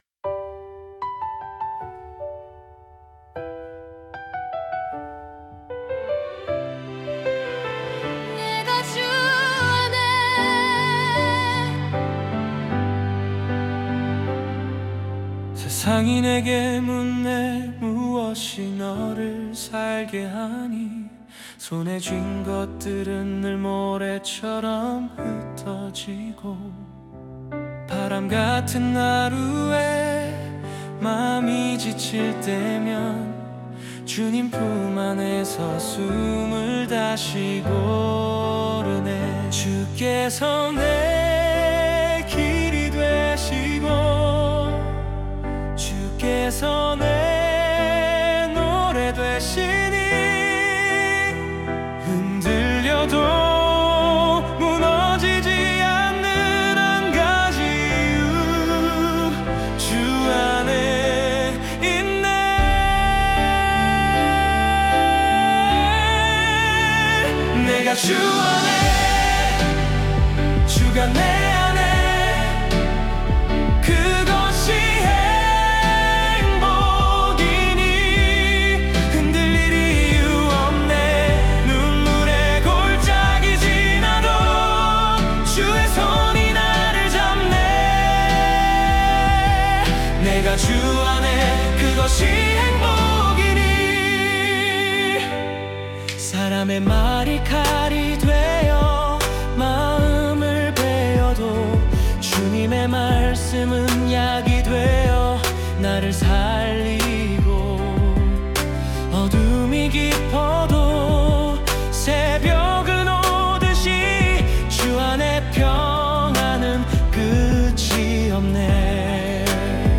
다운로드 설정 정보 Scene (장면) Topic (주제) Suno 생성 가이드 (참고) Style of Music Female Vocals, Soft Voice Lyrics Structure [Meta] Language: Korean Topic: [Verse 1] (조용한 시작, 의 분위기를 묘사함) ...
[Chorus] (메인 테마, calm_warm 느낌으로) ...